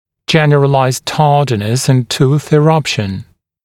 [‘ʤen(ə)r(ə)laɪzd ‘tɑːdɪnəs ɪn tuːθ ɪ’rʌpʃ(ə)n][‘джэн(э)р(э)лайзд ‘та:динэс ин ту:с и’рапш(э)н]общая задержка прорезывания зубов